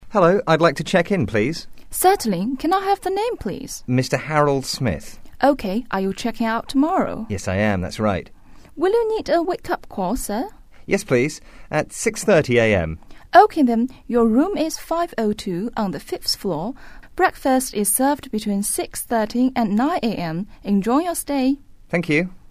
英语初学者口语对话第26集：酒店前台接待
english_40_dialogue_1.mp3